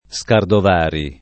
Scardovari [ S kardov # ri ]